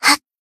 BA_V_Shiroko_Ridingsuit_Battle_Shout_2.ogg